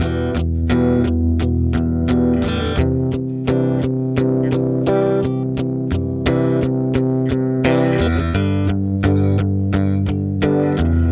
Track 02 - Guitar Rhythm 02.wav